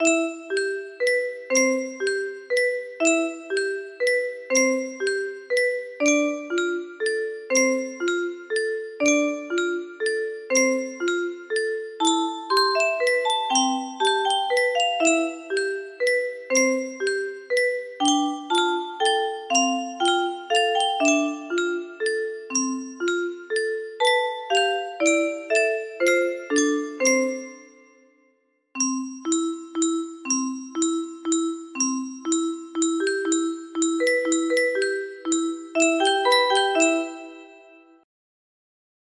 18 ABRIL 2022 music box melody